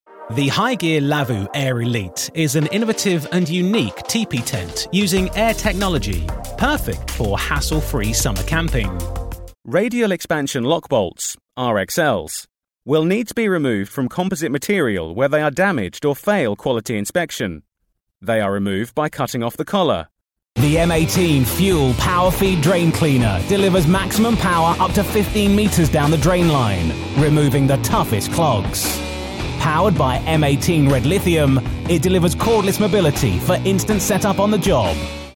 Inglés (Británico)
Comercial, Natural, Amable, Cálida, Empresarial
Explicador